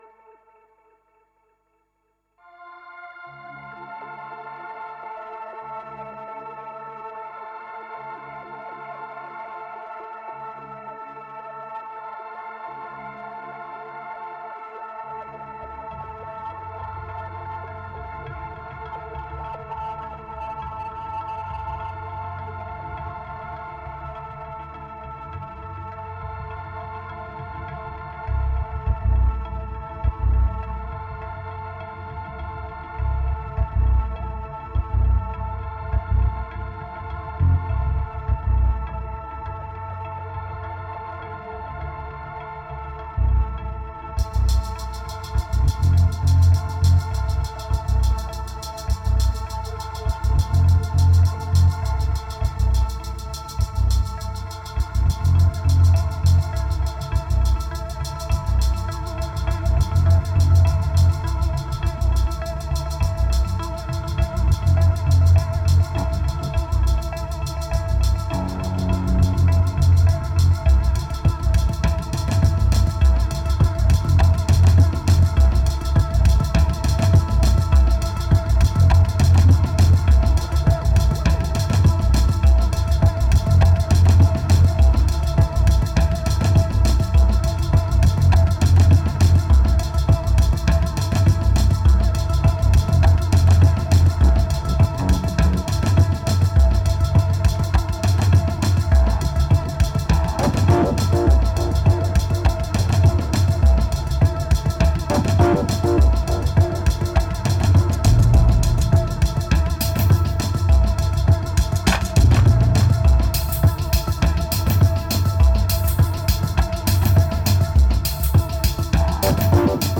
2171📈 - -19%🤔 - 102BPM🔊 - 2011-09-03📅 - -598🌟